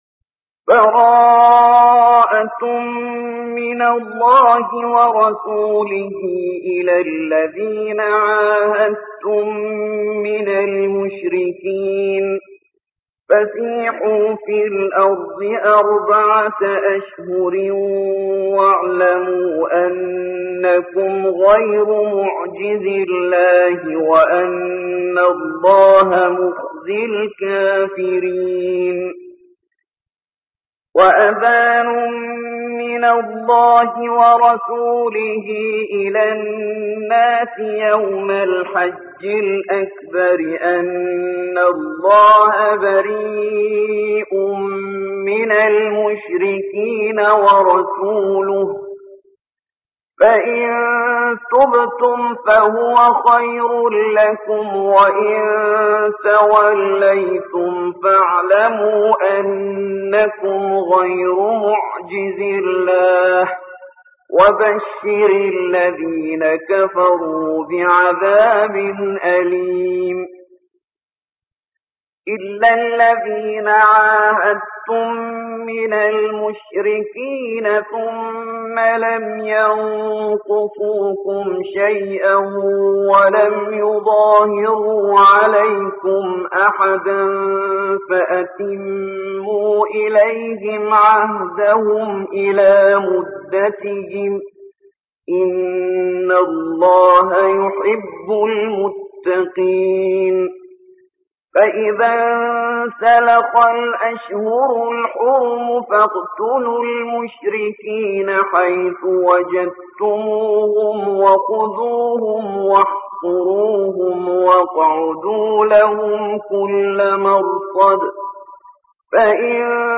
9. سورة التوبة / القارئ